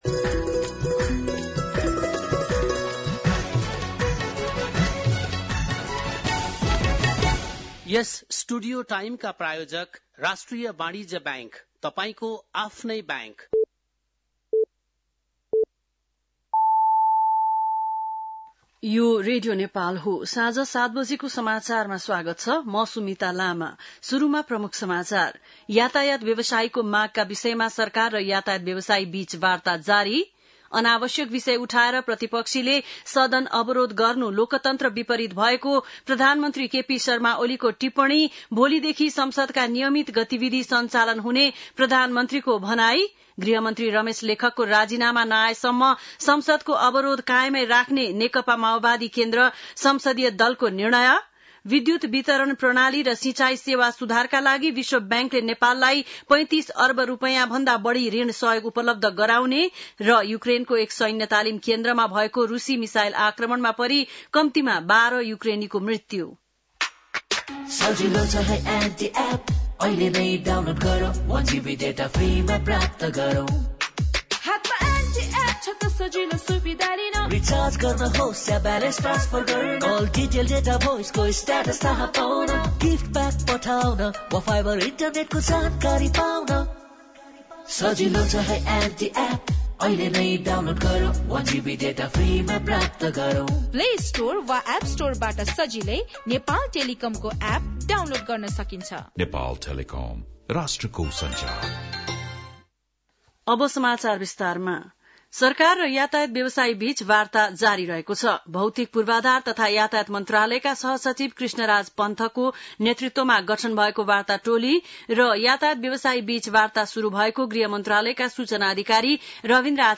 बेलुकी ७ बजेको नेपाली समाचार : १९ जेठ , २०८२
7-PM-Nepali-NEWS-.mp3